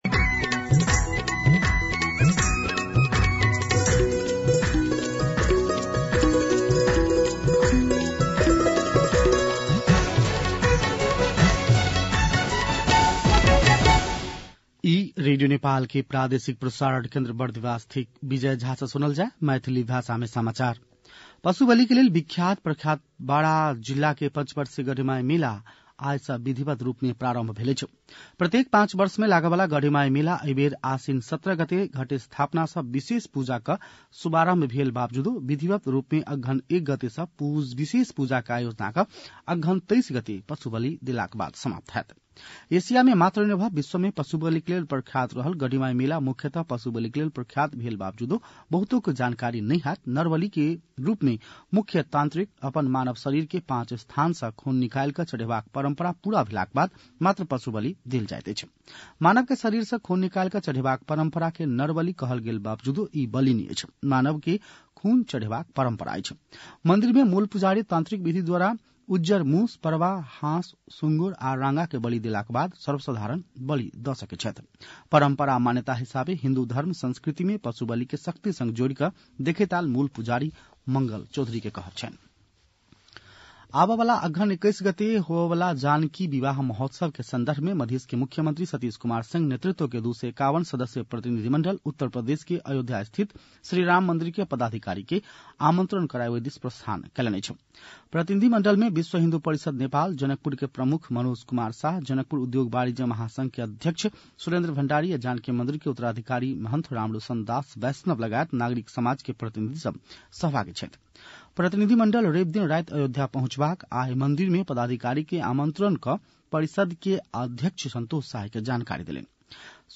मैथिली भाषामा समाचार : २ मंसिर , २०८१
Maithali-News-8-1.mp3